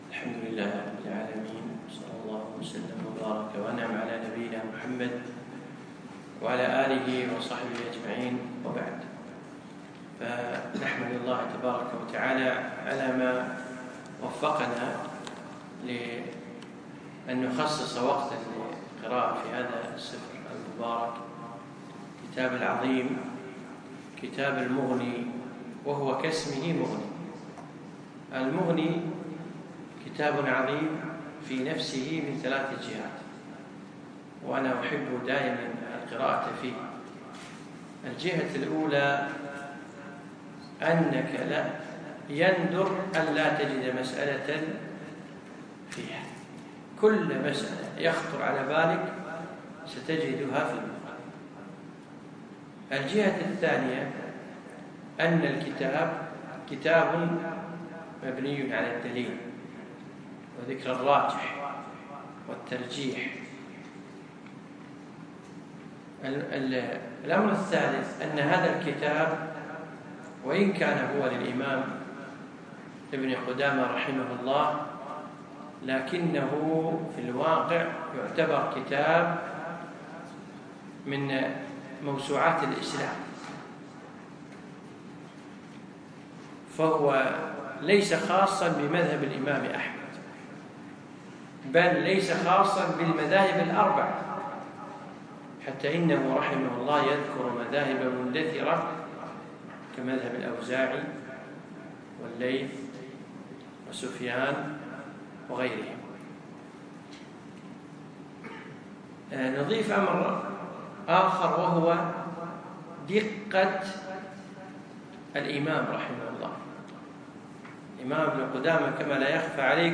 يوم الخميس 26 شعبان 1437هـ الموافق 2 6 2016م في مسجد أحمد العجيل القصور
الدرس الأول